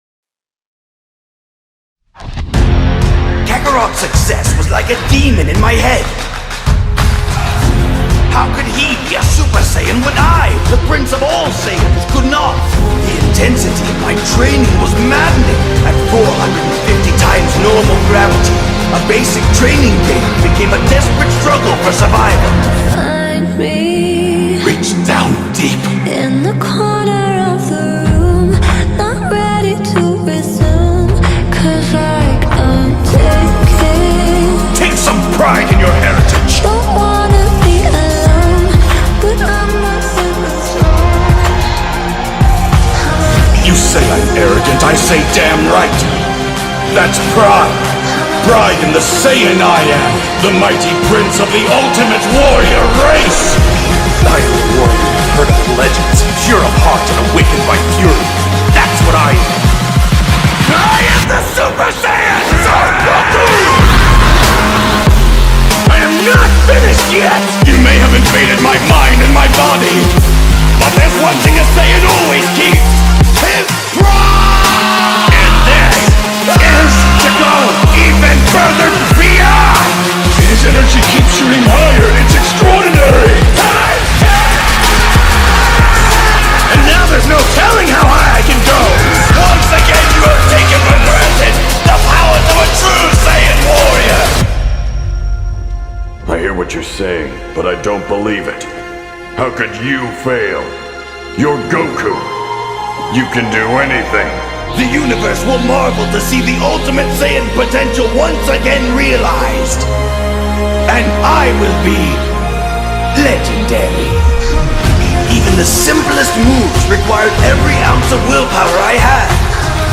It's a fan mix